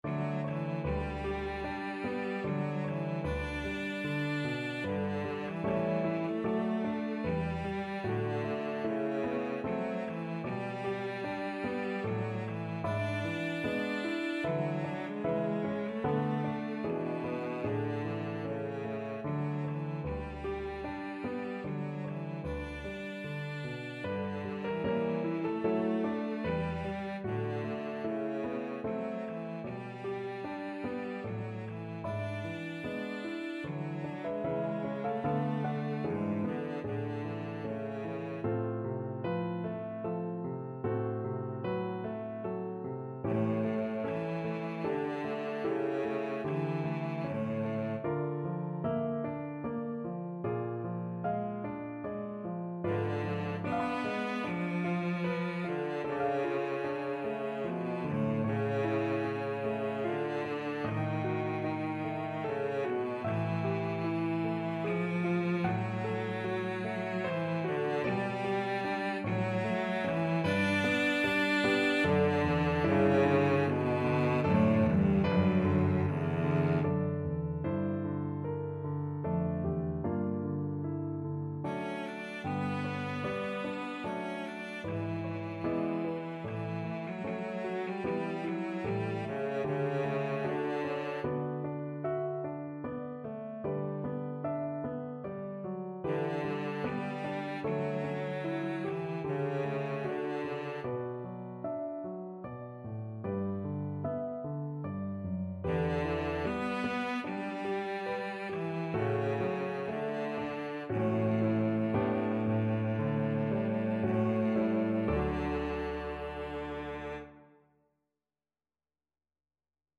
Classical Brahms, Johannes Intermezzo, A Major Op.118 No.2 Cello version
Cello
D major (Sounding Pitch) (View more D major Music for Cello )
Andante teneramente = 75
3/4 (View more 3/4 Music)
Classical (View more Classical Cello Music)